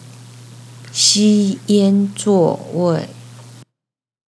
シー イェン ズゥォ ウェイ
xī yān zuò wèi